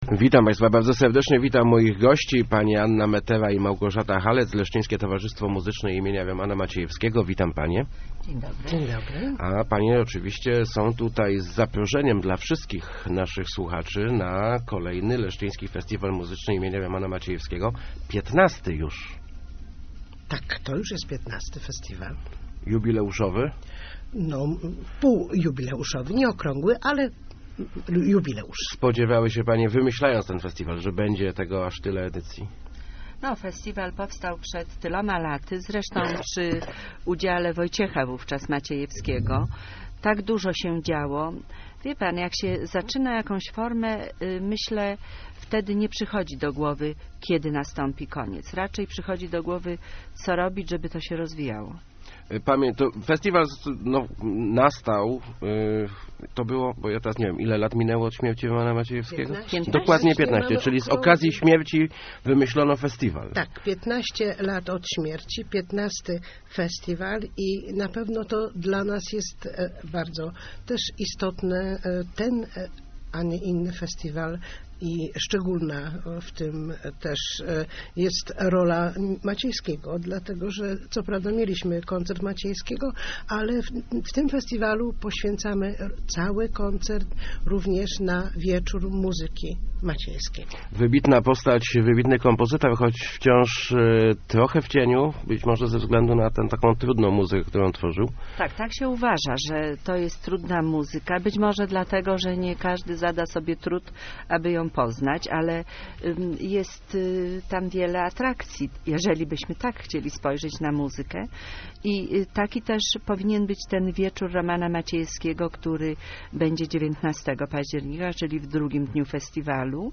Start arrow Rozmowy Elki arrow Festiwal Romana Maciejewskiego